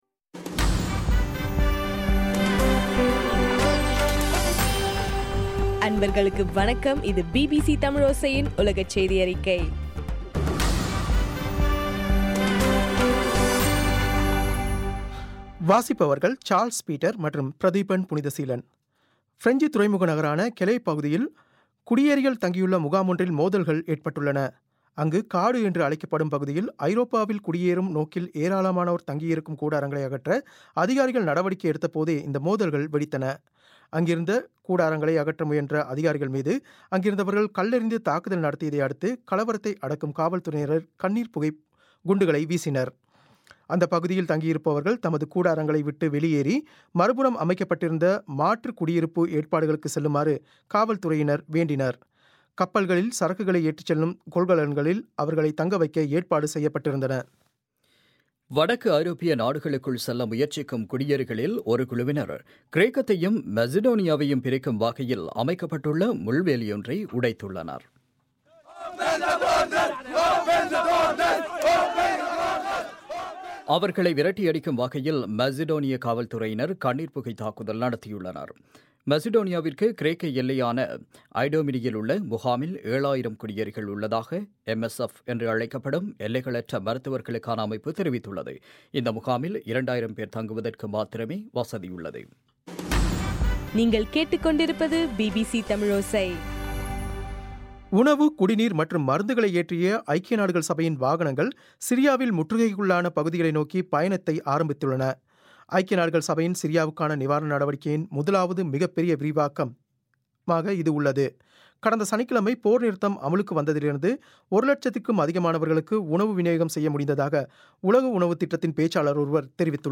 பிபிசி தமிழோசை உலகச் செய்தியறிக்கை- பிப்ரவரி 29